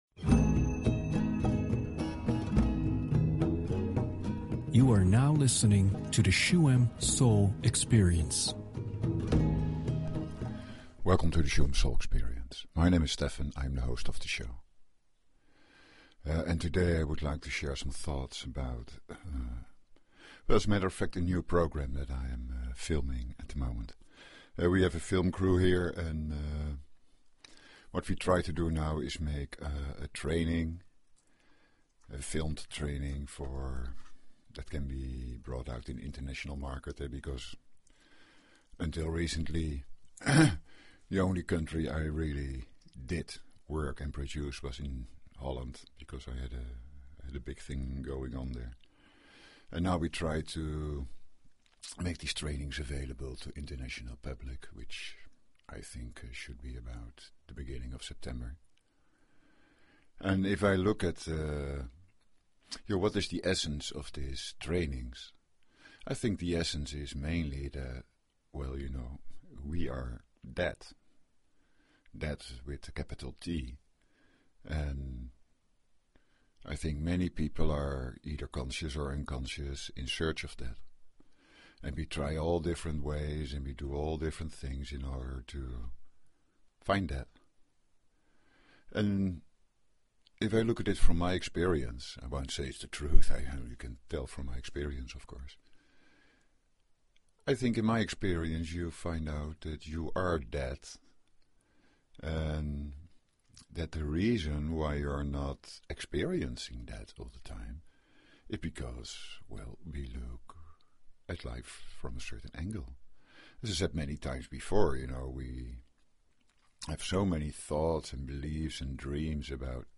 The second part of the show is a Reset Meditation. This shamanic ritual eases to let go of inner turmoil and contact the inner peace that is inside all of us. Let the sounds and energy flow through and take with them your worries and stress.